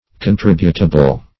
Contributable \Con*trib"u*ta*ble\, a. Capable of being contributed.